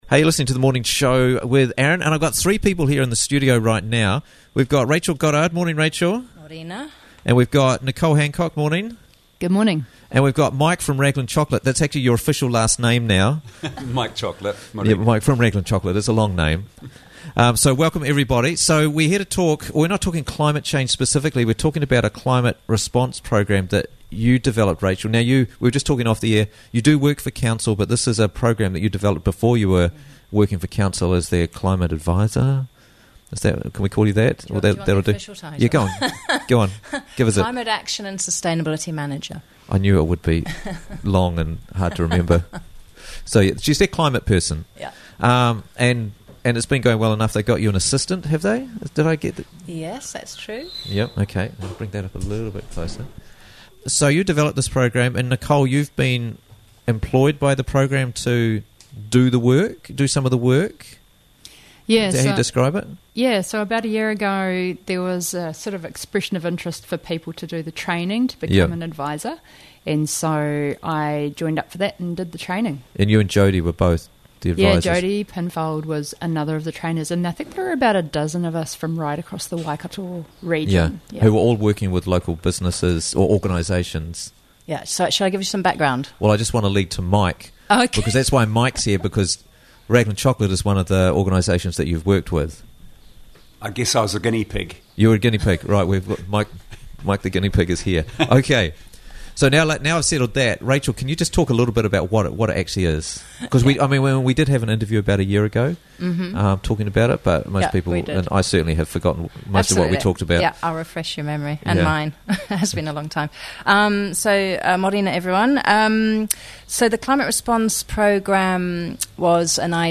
Raglan Chocolate's Climate Response - Interviews from the Raglan Morning Show